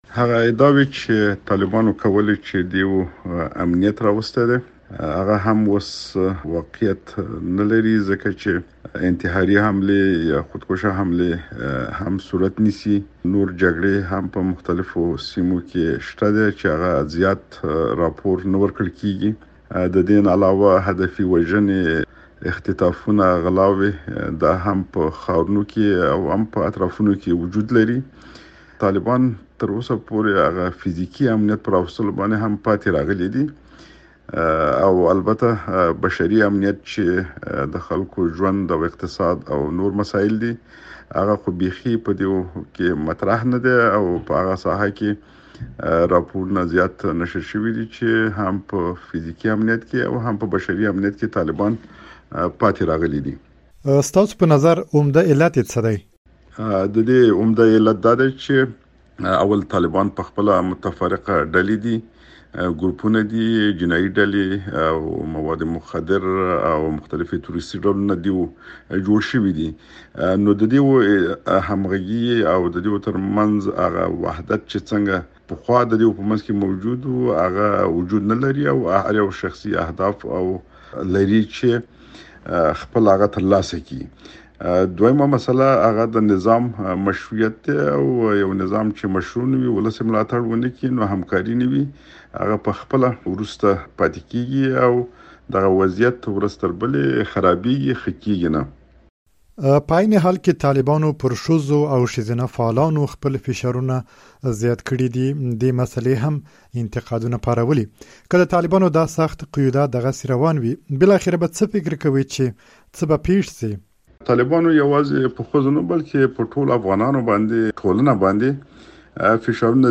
د شاه محمود مياخېل مرکه